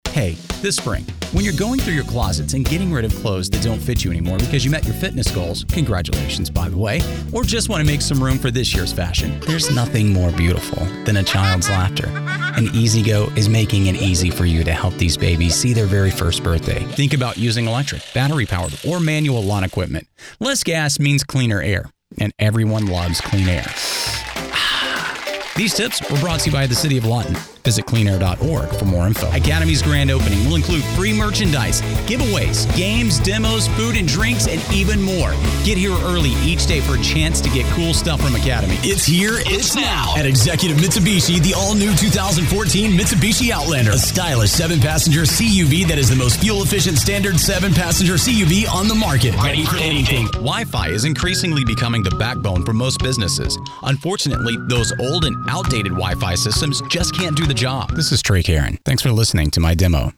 Elevate your narratives and commercials with the seasoned touch of a professional voice artist